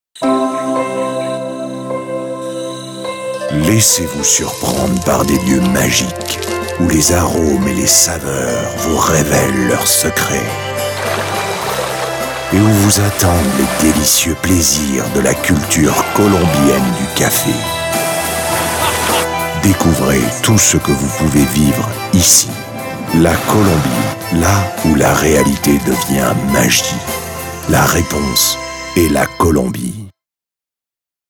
Deep and sensual bass baritone voice. Voix grave, profonde, sensuelle.
spanisch Südamerika
Sprechprobe: Industrie (Muttersprache):